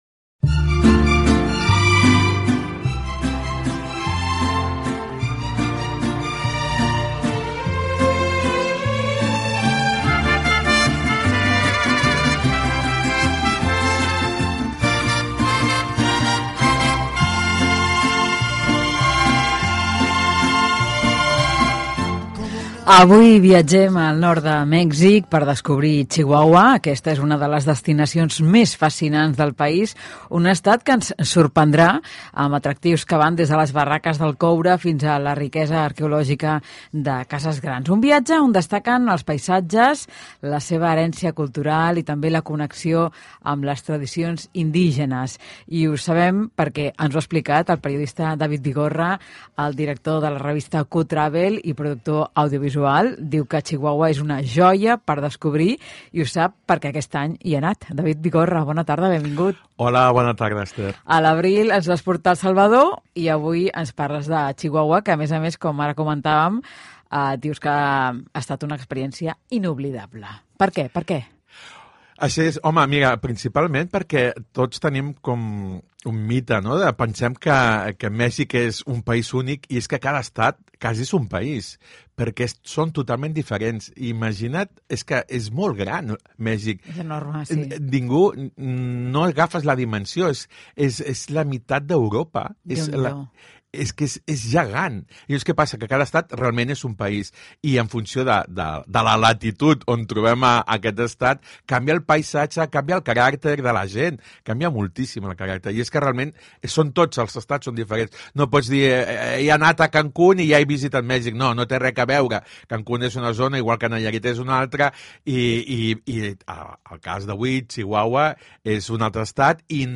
Escucha el reportaje completo en catalán en la web de RAC1 o directamente aquí: